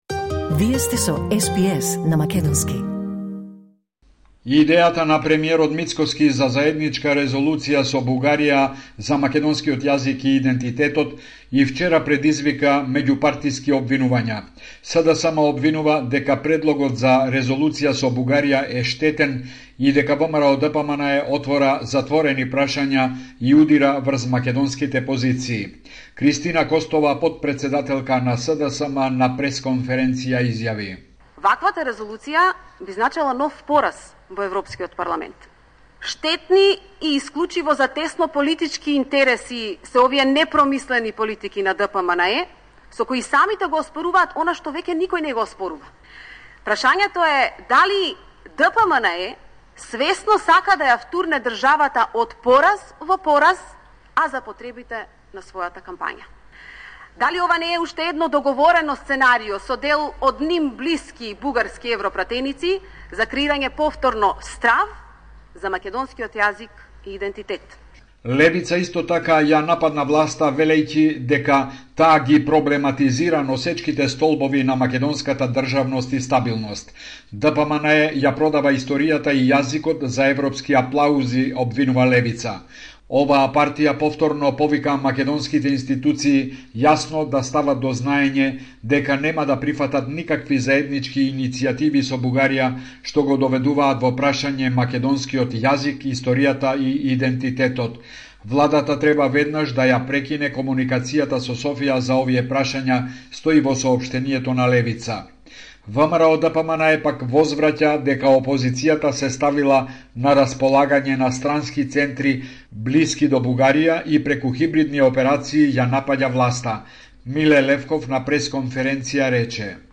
Извештај од Македонија 23 јули 2025